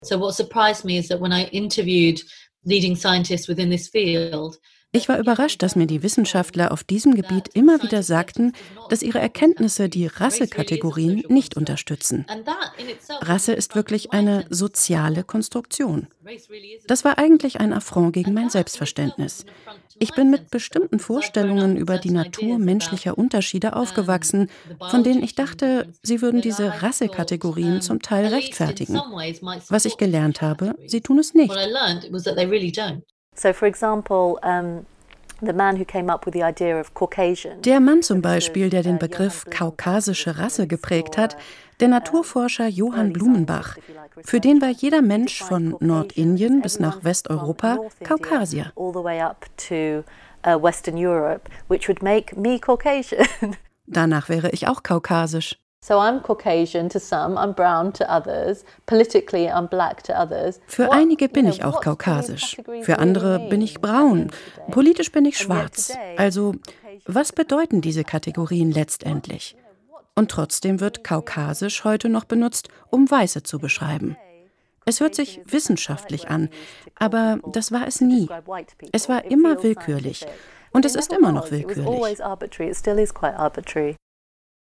Ihre sanfte, klare und warme Stimme wurde bereits für Werbung, zahlreiche Hörspiele und Features, u. a. für Der Hörverlag, Ravensburger und Europa, aber auch für Radioproduktionen von Deutschlandfunk, RBB, Deutschlandradio Kultur, Hessischer Rundfunk oder den WDR eingesetzt.
Sprechprobe: Sonstiges (Muttersprache):